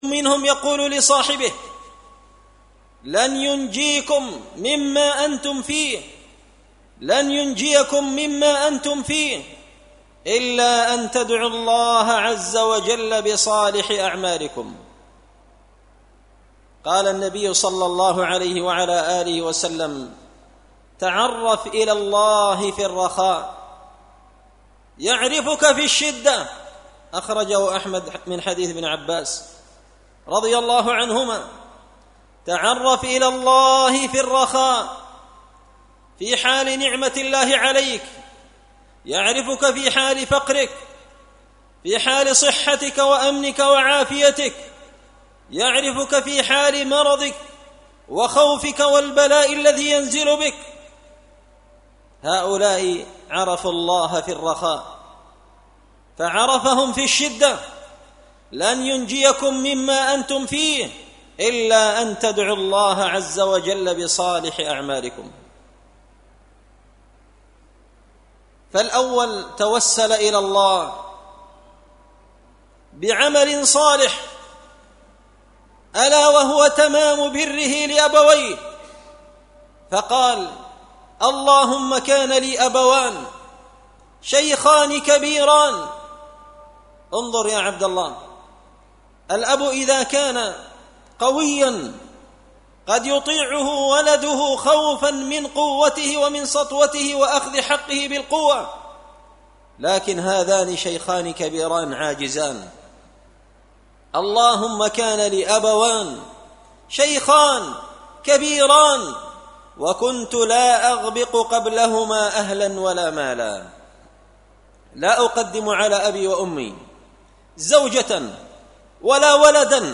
خطبة جمعة بعنوان – تعرف على الله في الرخاء يعرفك في الشدة
دار الحديث بمسجد الفرقان ـ قشن ـ المهرة ـ اليمن